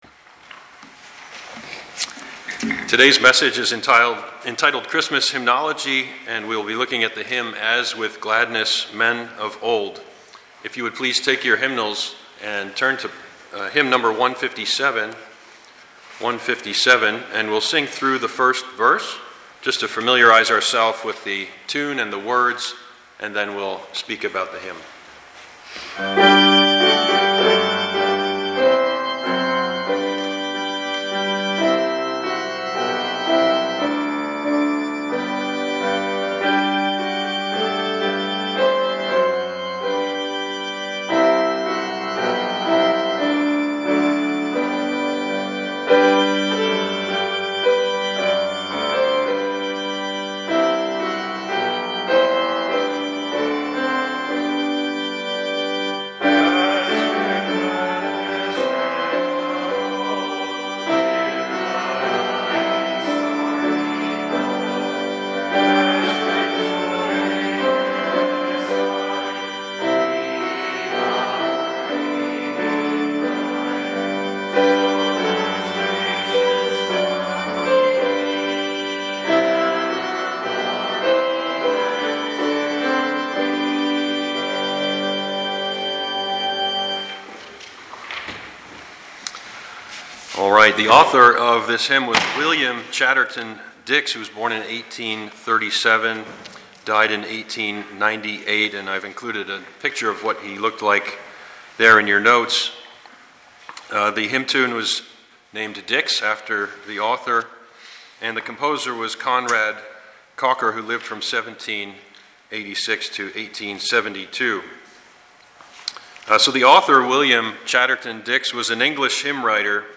Passage: Matt. 2:1-12 Service Type: Sunday morning « Studies in the Book of Revelation #12A